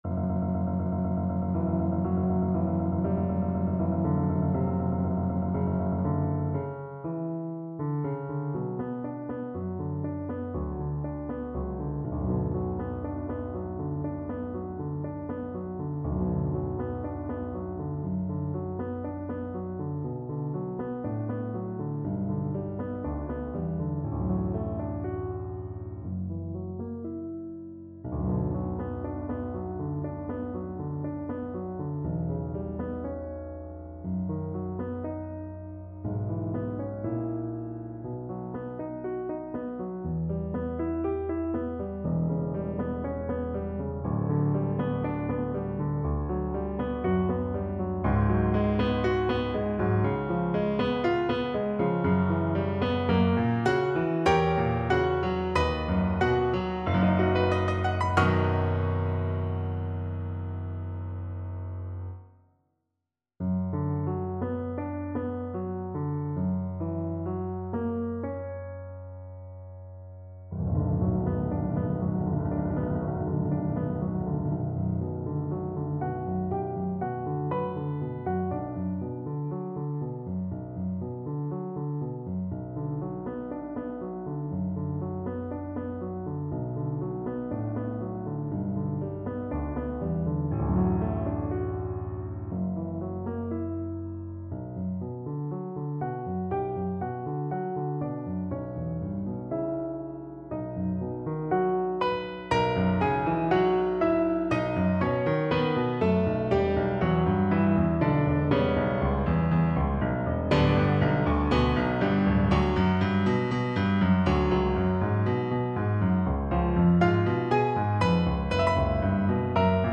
Piano Playalong MP3